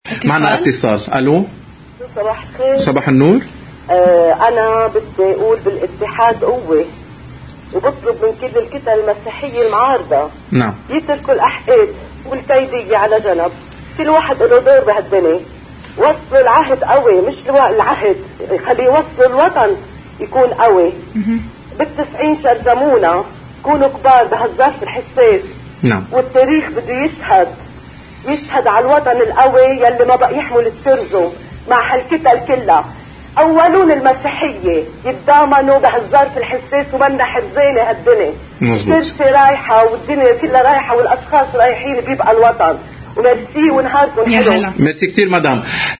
بكلمة نابعة من القلب، نقلت إحدى السيدات دعوتها لإتحاد اللبنانيين والمسيحيين، ومما قالته ضمن برنامج